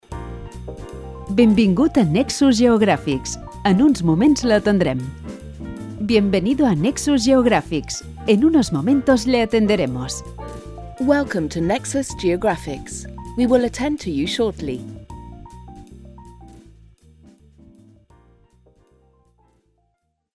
Mensaje de bienvenida en catalán, castellano e inglés. Música de Escena Digital